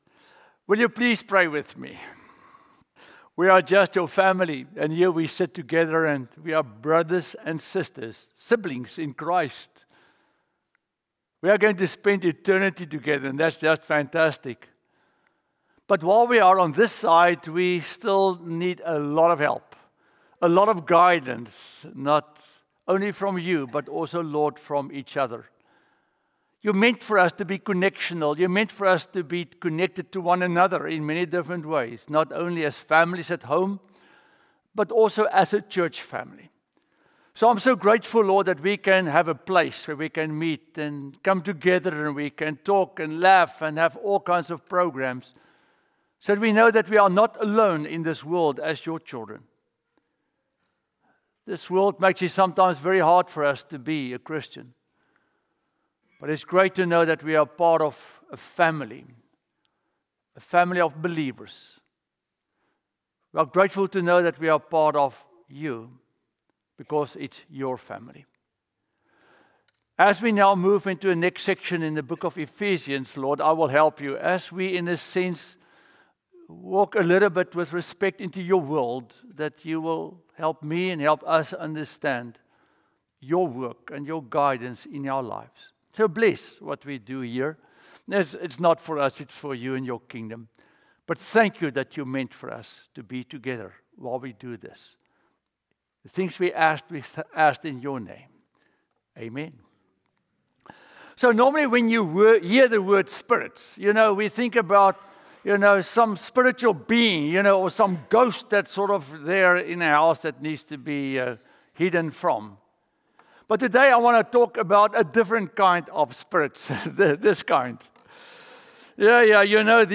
Spirits and the Holy Spirit: Sermon (Audio)